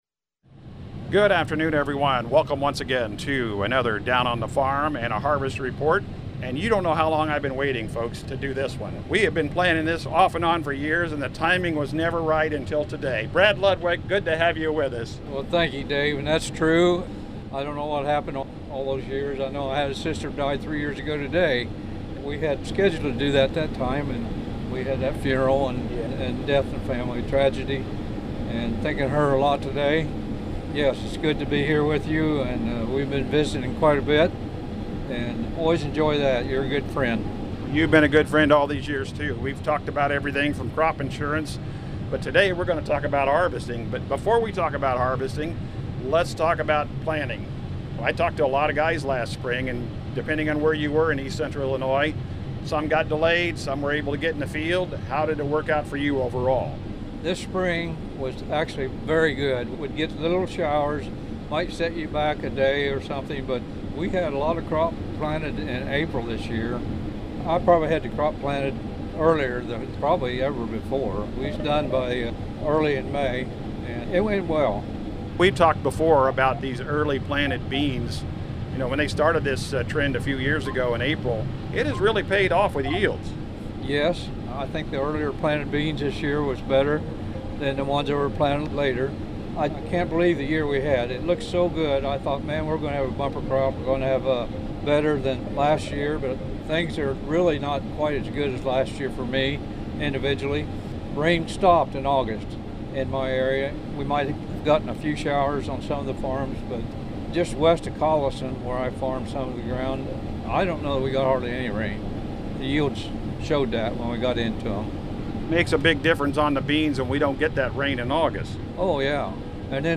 in a cornfield north of Newtown, IL.